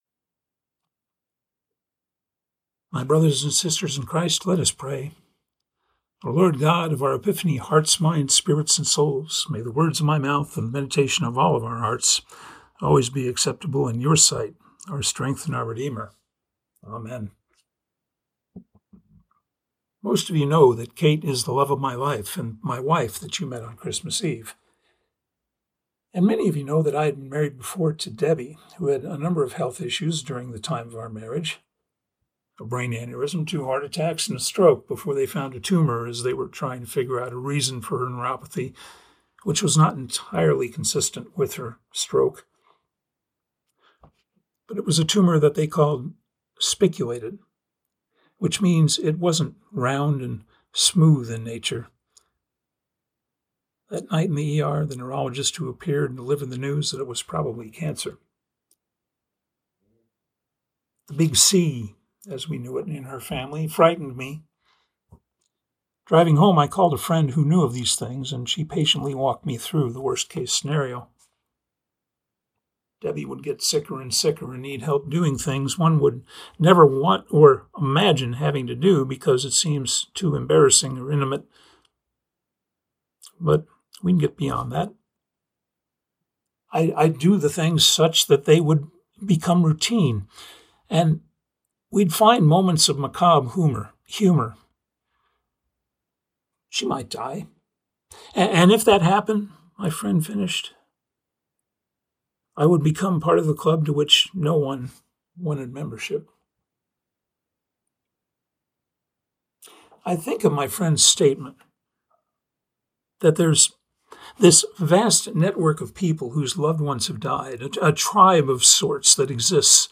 Baptism-of-Christ-Sermon-Mk-1-4-11-Welcome-to-the-Club.mp3